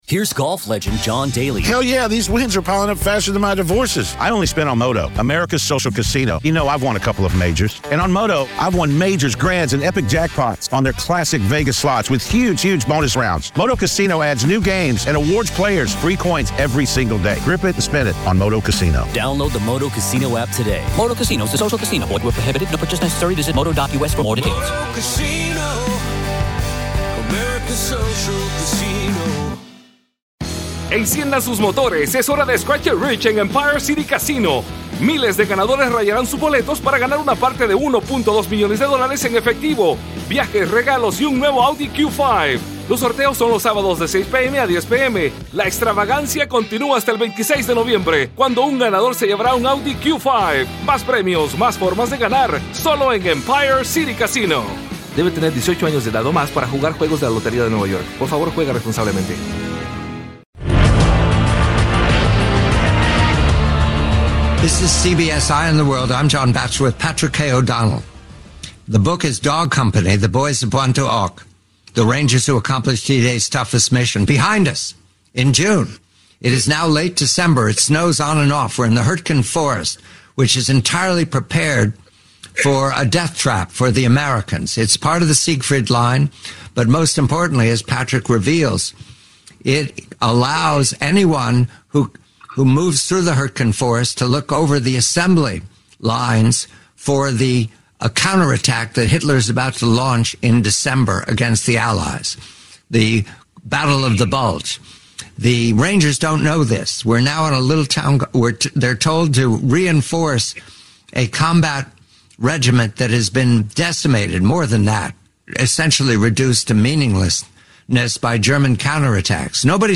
Blackstone Audio, Inc. Audible Audiobook – Unabridged